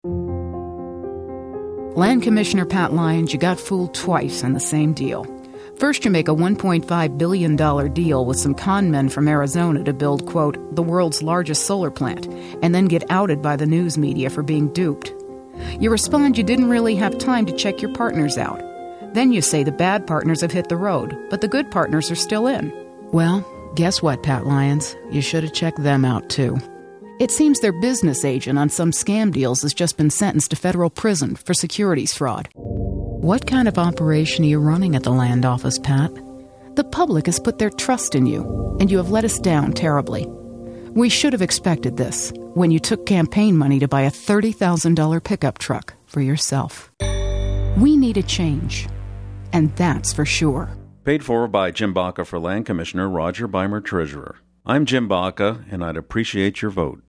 Meanwhile the Baca campaign has launched a new radio ad calling attention to several recent financially questionable boondoggles by Lyons while serving his current term.